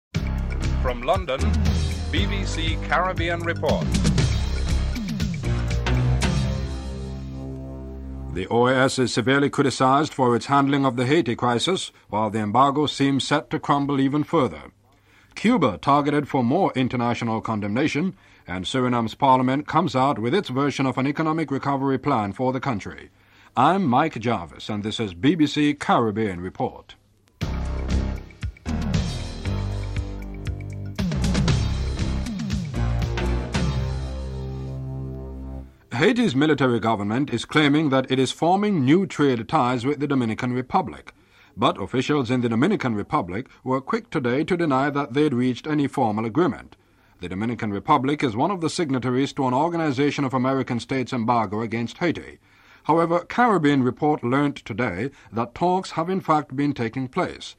1. Headlines (00:00-00:34)
6. Report on embargo against Haiti and CARICOM perspective given from interview with Eugenia Charles, Prime Minister of Dominica (06:48-09:16)